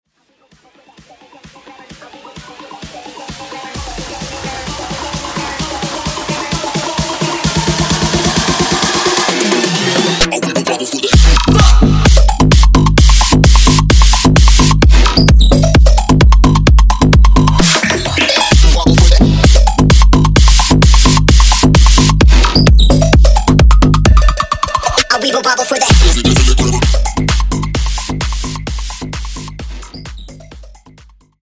bailen Y gozen todos del sabor Costeno Colombiano